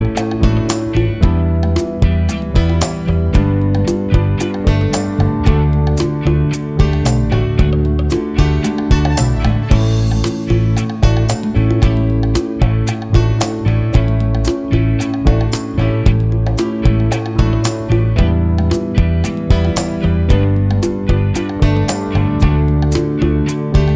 Minus Lead Guitar Pop (1970s) 4:30 Buy £1.50